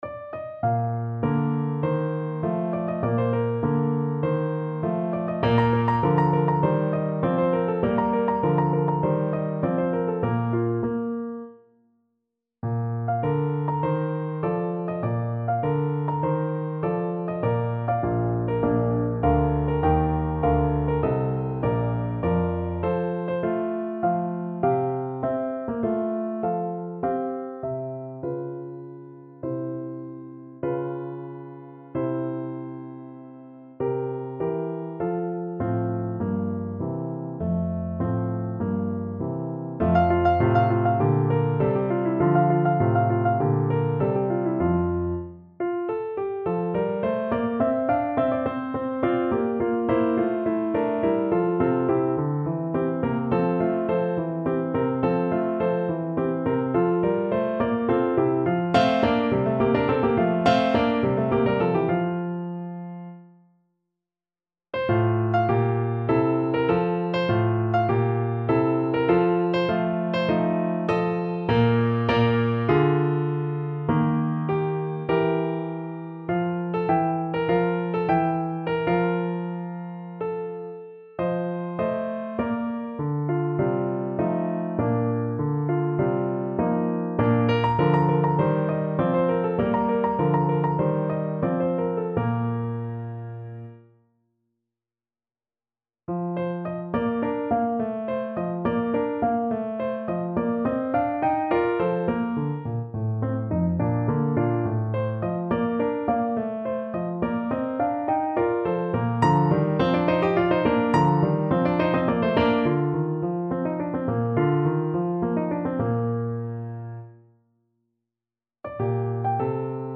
~ = 50 Larghetto
Classical (View more Classical Clarinet Music)